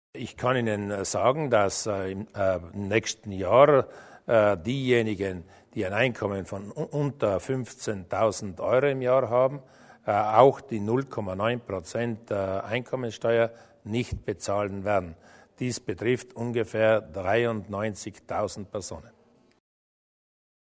Landeshauptmann Durnwalder zur Einkommenssteuer IRPEF für Niedrigverdiener